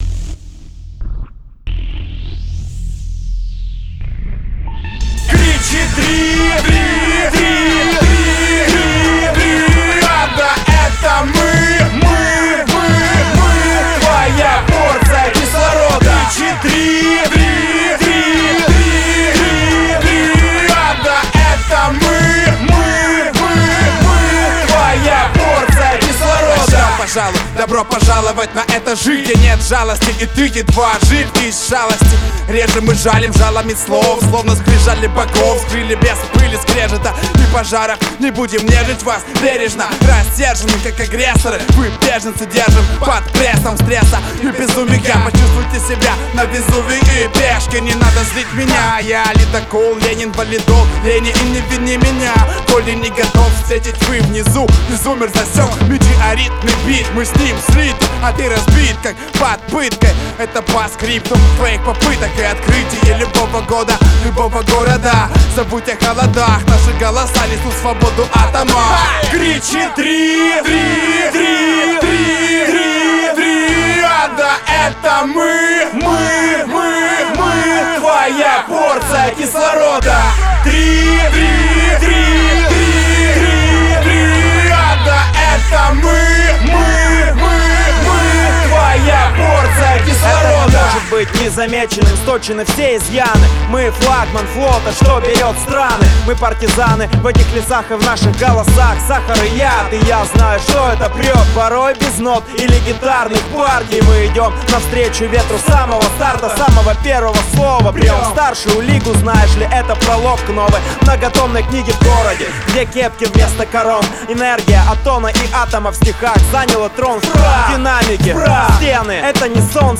РэпЧина [631]